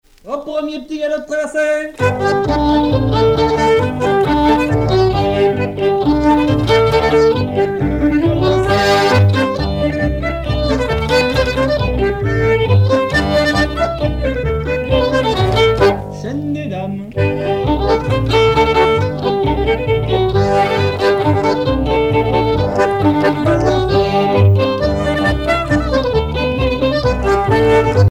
Couplets à danser
danse : quadrille : petit galop
Pièce musicale éditée